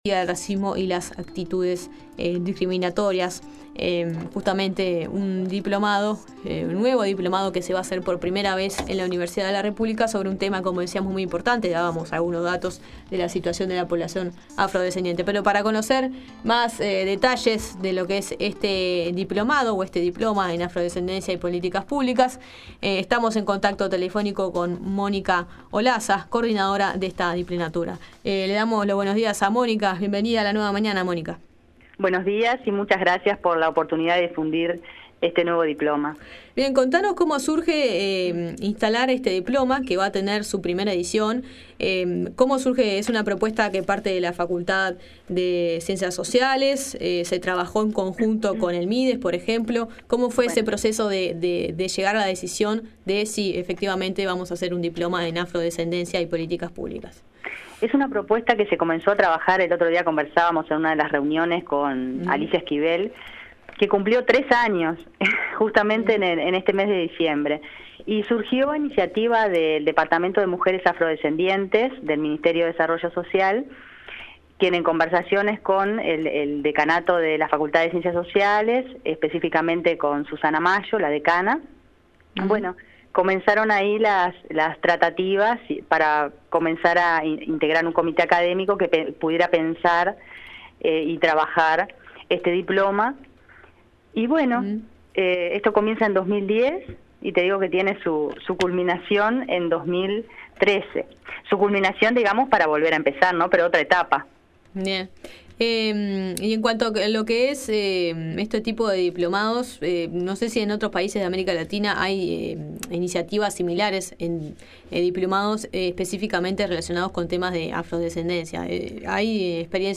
Para conocer más sobre este Diploma en afrodescendencia y politicas públicas, La Nueva Mañana dialogó